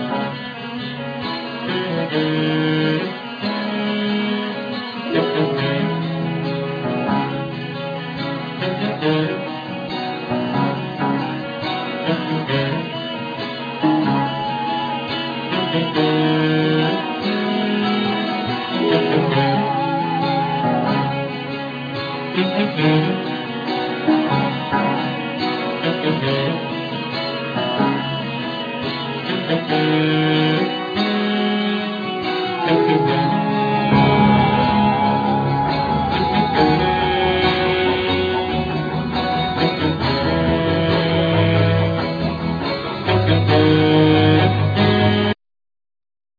Vocal
Mandolin,Guitar
Saxophone,Clarinet,Keyboards,Whistle,Okarina
Cello,Violin,Bass